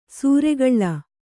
♪ sūregaḷḷa